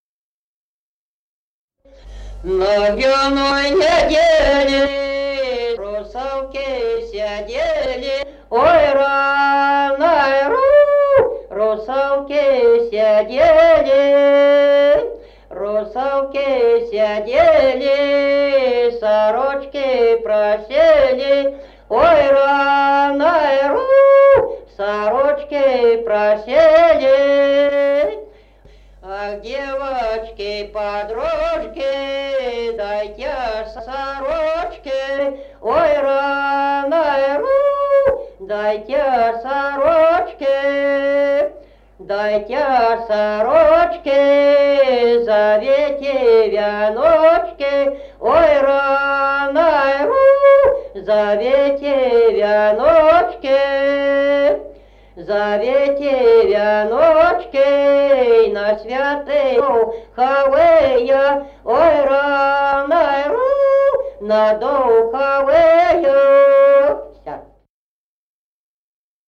Народные песни Стародубского района «На гряной неделе», гряная.